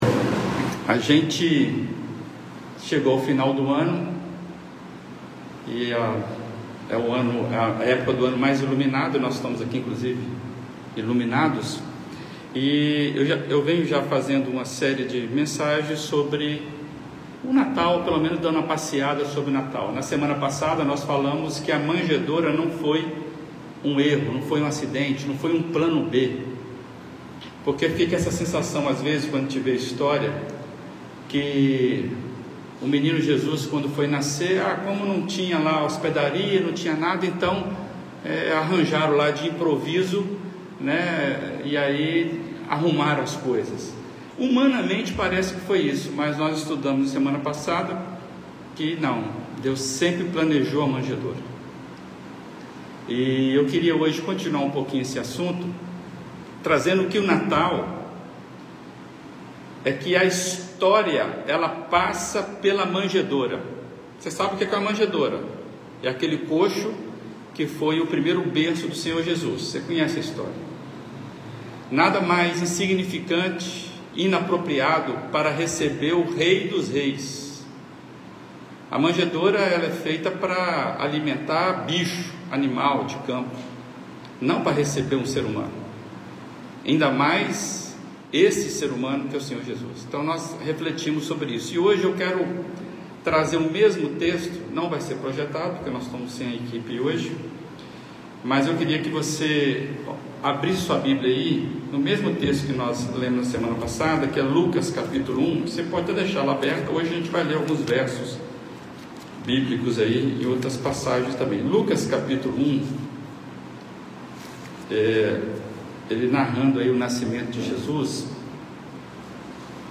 Reflexões das Quartas-feiras